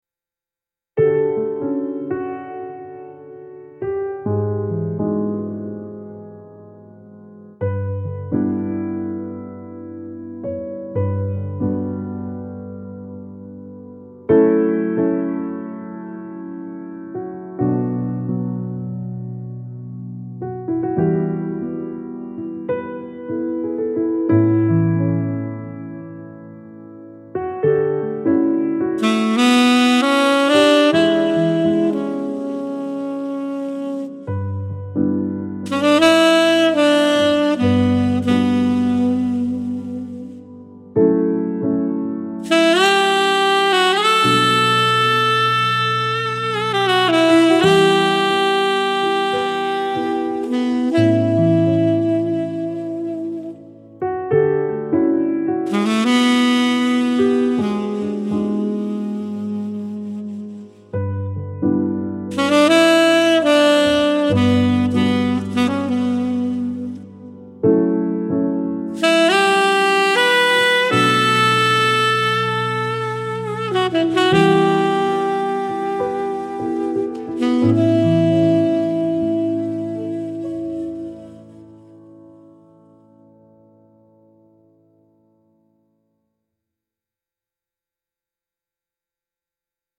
classic jazz standard style ballad with warm saxophone and piano